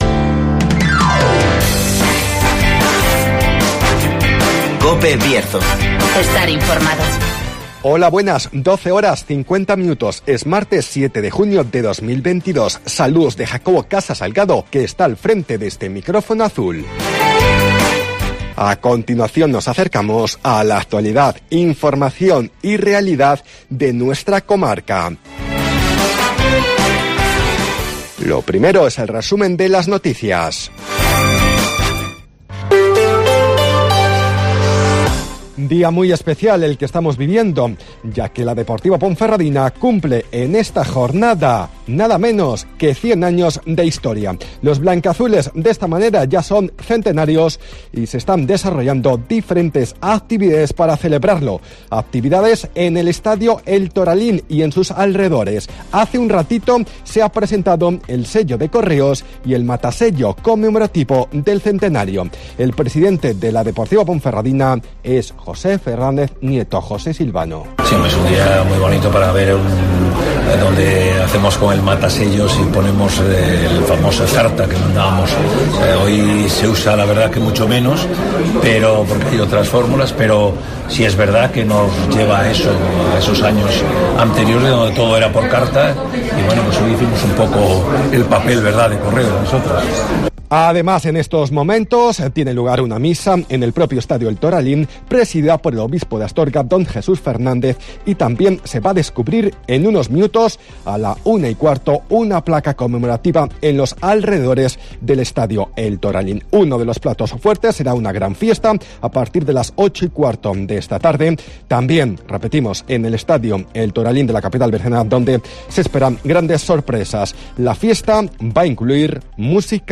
Resumen de las noticias, El Tiempo y Agenda.